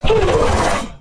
hurt1.ogg